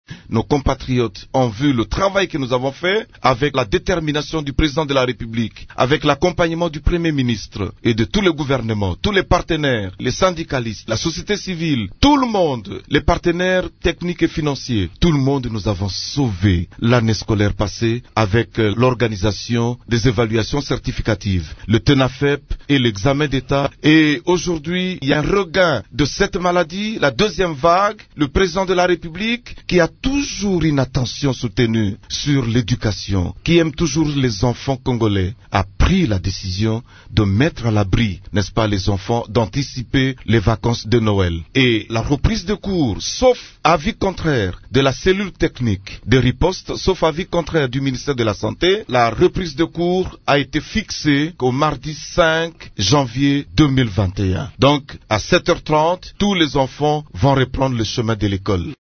La reprise des cours pour les élèves du primaire et du secondaire pourrait avoir lieu le mardi 5 janvier 2021, a annoncé mardi 2 janvier 2021, le ministre de l’Enseignement primaire, secondaire et technique (EPST), Willy Bakonga, dans une interview exclusive accordée à Radio Okapi.
Suivez le ministre Willy Bakonga dans cet extrait :